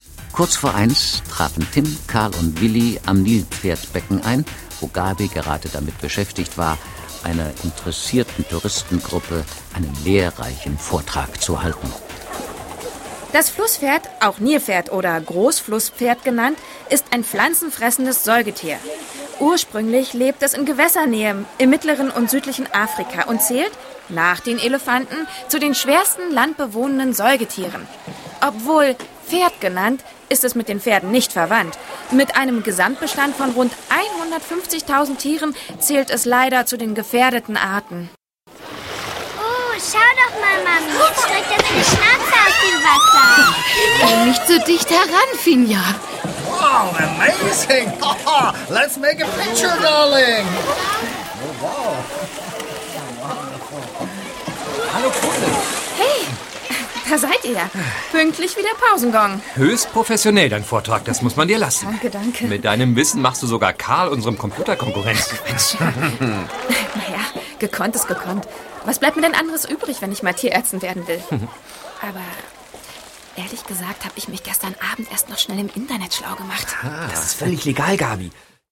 Ravensburger TKKG 178 - Hai-Alarm im Aquapark ✔ tiptoi® Hörbuch ab 6 Jahren ✔ Jetzt online herunterladen!
Hoerprobe-TKKG-178-Haialarm_im_Aquapark.mp3